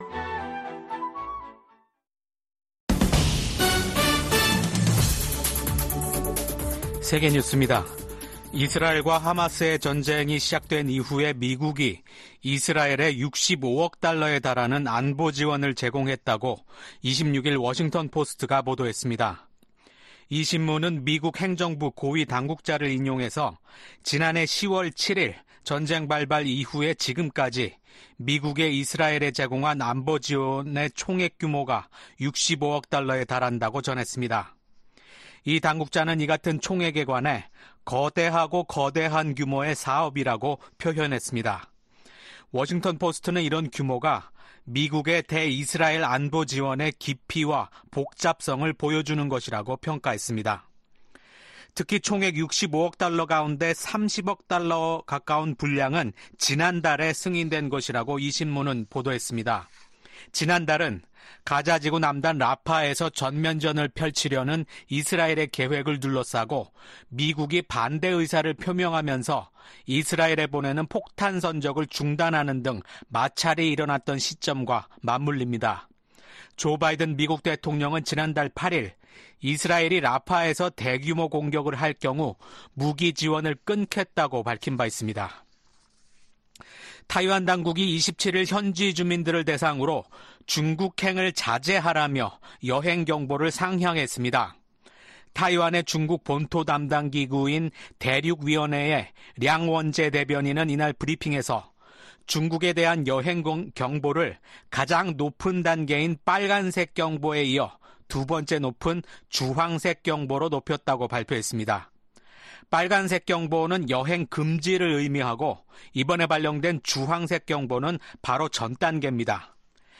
VOA 한국어 아침 뉴스 프로그램 '워싱턴 뉴스 광장' 2024년 6월 28일 방송입니다. 미국 정부는 러시아의 우크라이나 점령지로 북한 노동자가 파견될 가능성에 반대 입장을 분명히 했습니다. 존 허브스트 전 우크라이나 주재 미국 대사는 북한이 우크라이나에 군대를 파병할 가능성은 높지 않다고 말했습니다. 북한은 처음으로 진행한 다탄두 미사일 시험발사가 성공적이었다고 주장했습니다.